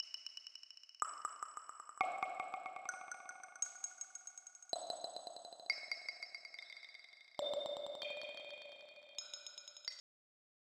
I’ll start with one of mine: a freeverb-based reverberation unit. It supports both mono and stereo input signals, and outputs in stereo.